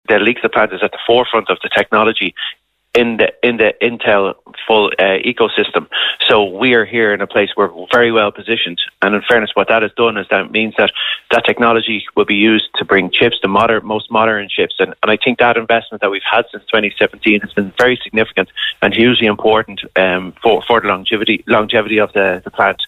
Speaing on Kildare Today, Kildare North TD Joe Neville said Liexlip has seen significant investment leaving workers here well positioned to ensure the longevity of the plant.